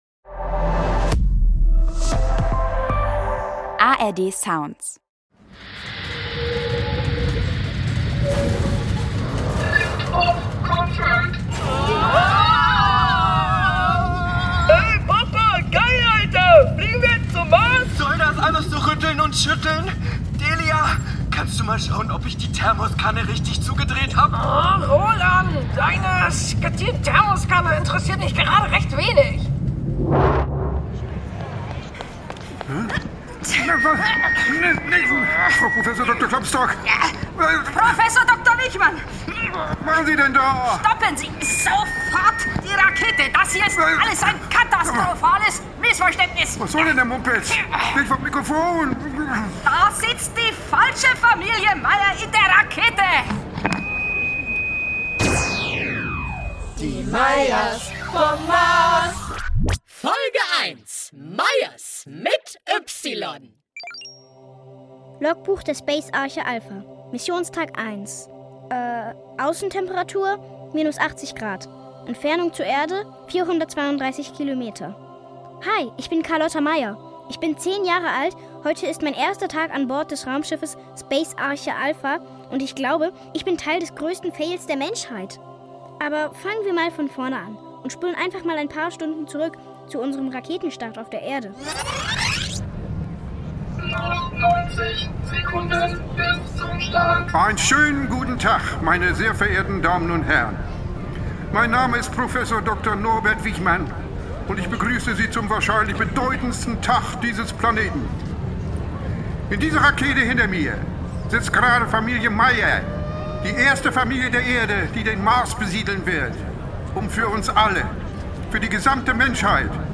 futuristische Familien-Comedy